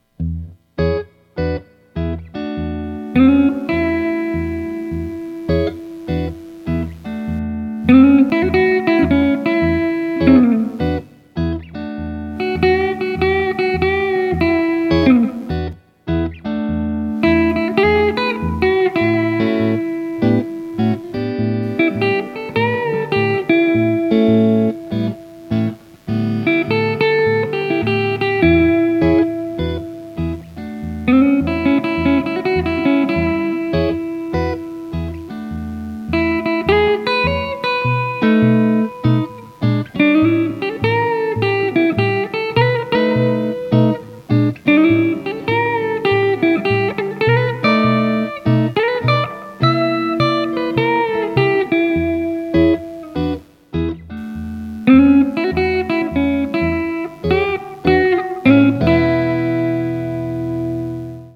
Learn to play blues guitar.
It was also my first try at home recording. I bought a USB mic that I have in front of my Spark amp.
I have some background buzz but I think I’ve fixed it.
Nice and clear and the tone on your guitar is killer.
There’s a bit of compression, delay and reverb. I just added a tiny bit of a noise gate for removing the slight background hiss.
Congrats on your first post , you played that well , timing was spot on , the recording was loud and clear .